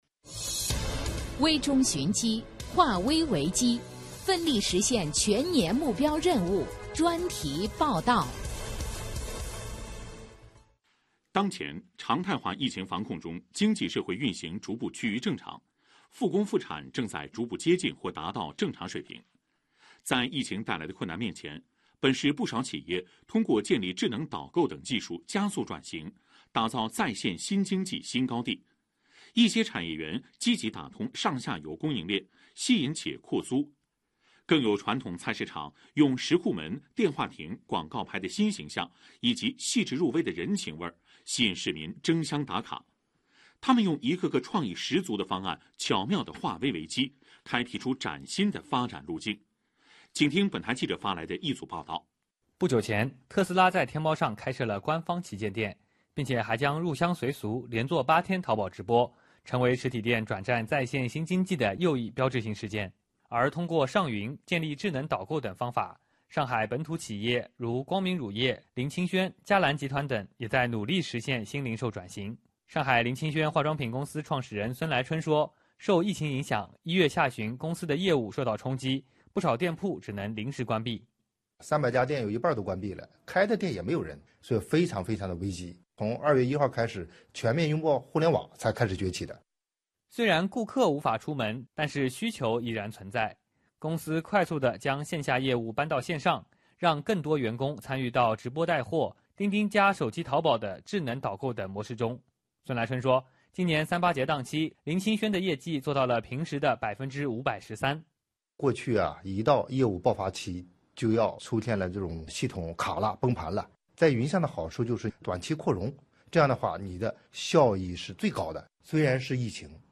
在线新经济报道.mp3